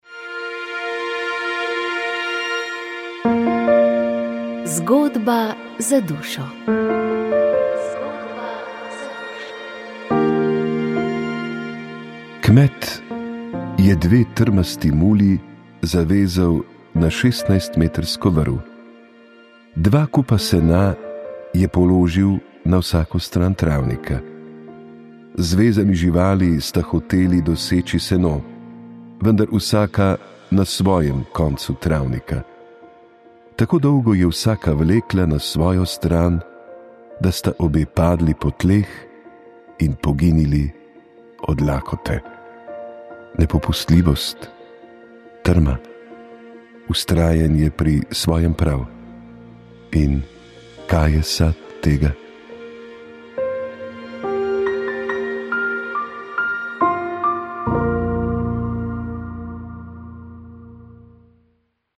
Posnetek programa Radia Ognjišče dne 01. november 2025 ob 05-ih